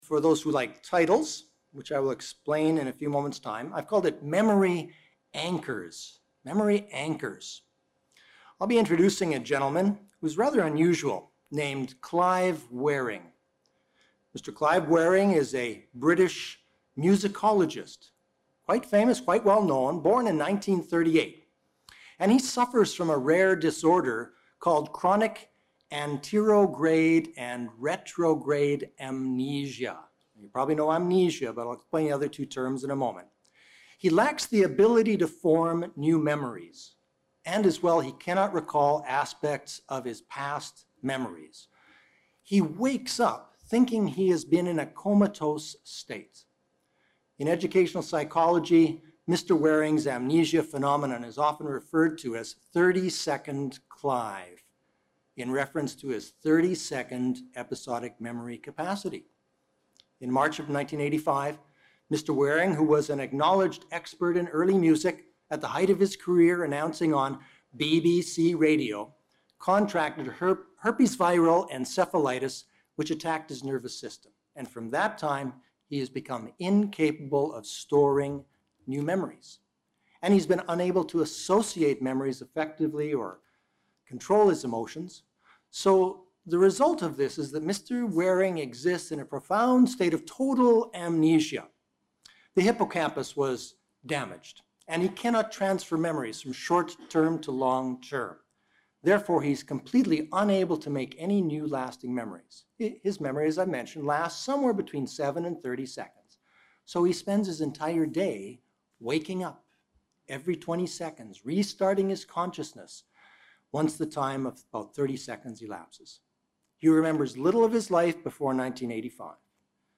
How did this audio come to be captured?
Given in Beloit, WI